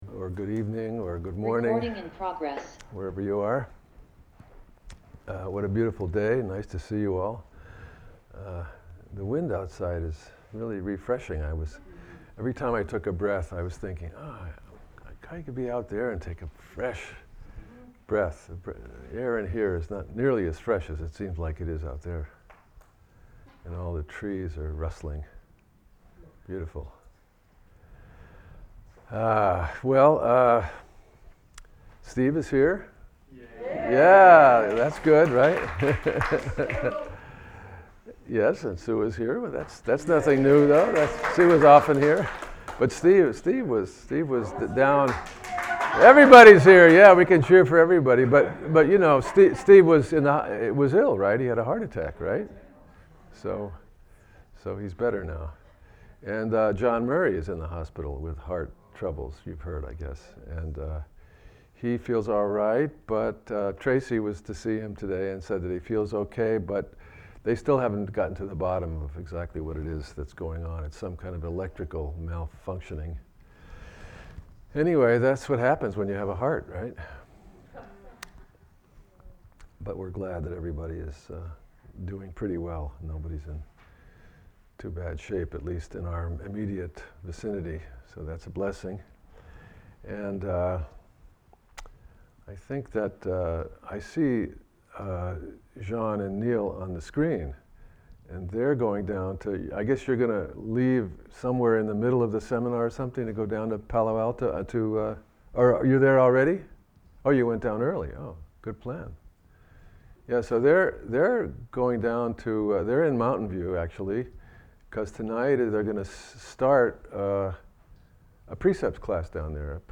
at the Everyday Zen Dharma Seminar. The Gandavyuha Sutra is the story of the spiritual pilgrimage of the monk Sudhana, who visits and learns from many spiritual masters. This is the final chapter 39 of the much larger Avatamsaka Sutra.